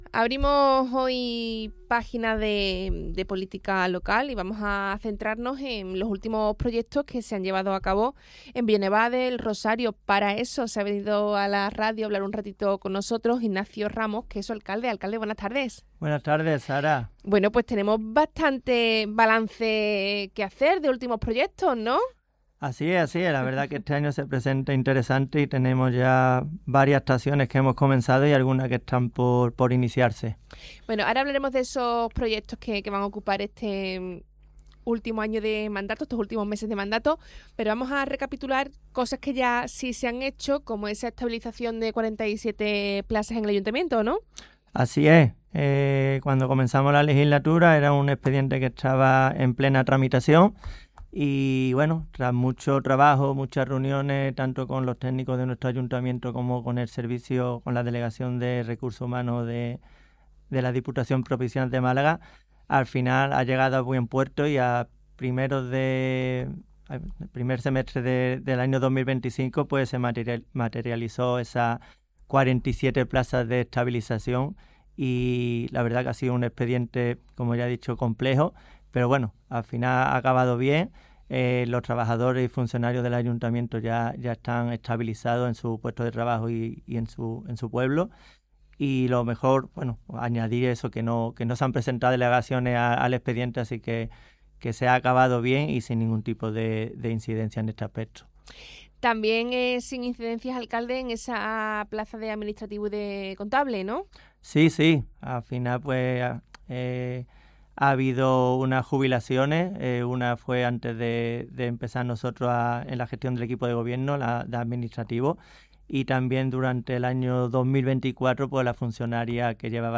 Entrevista Ignacio Ramos.